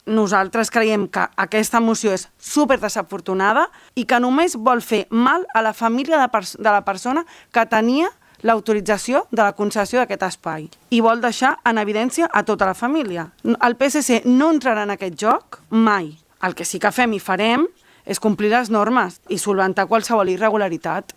Debat tens en l’última sessió plenària per les posicions enfrontades entre govern i oposició sobre la gestió del bar El Paso, que posarà punt final a la seva activitat amb el canvi d’any.